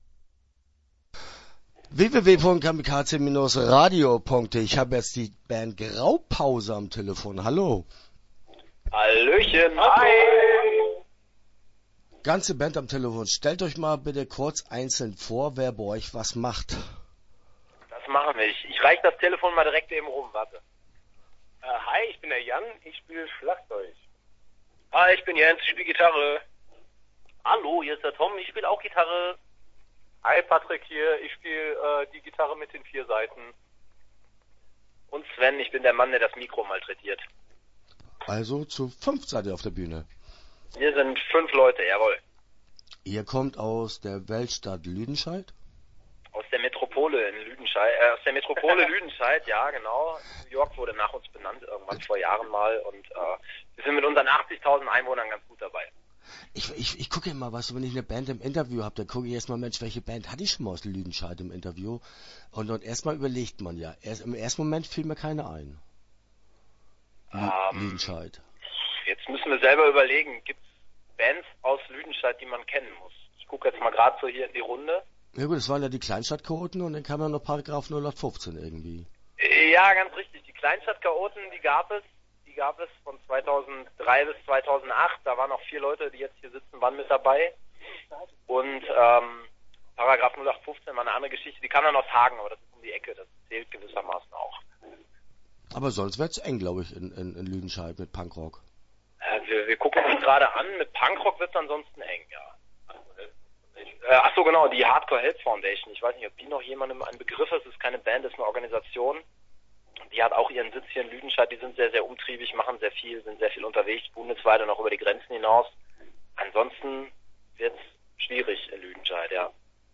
Start » Interviews » Graupause